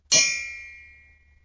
金属管 " 金属管1
描述：各种尺寸的金属管都用小金属螺栓敲击。用索尼爱立信手机麦克风录制。可用于工业或部落打击乐等。
标签： 金属 金属 部落 工业 钢铁
声道立体声